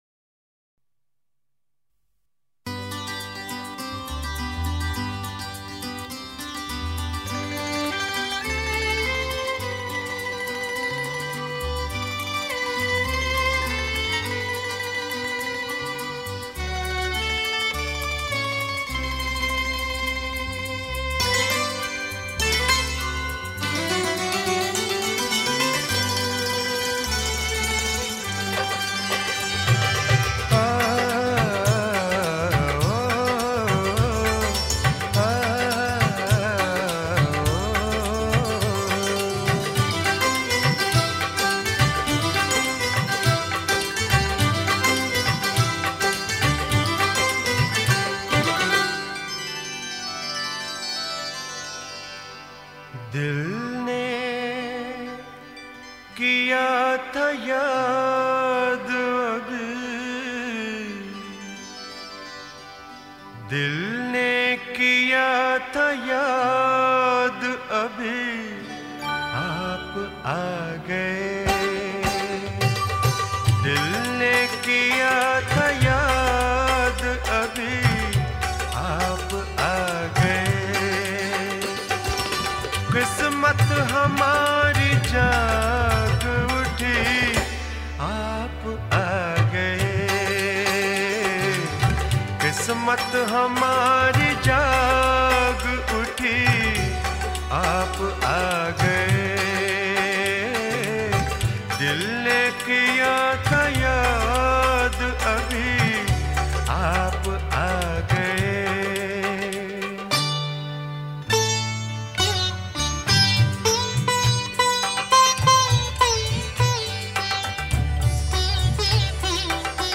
Ghazal